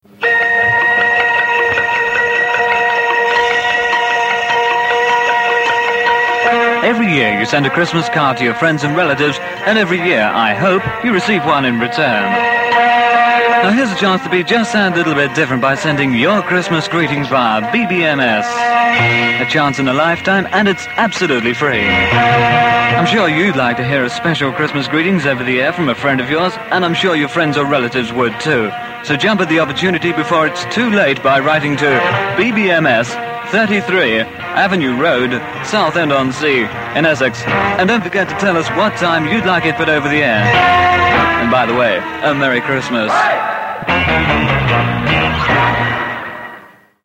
with a Christmas trail on BBMS from shortly before that station closed down in 1966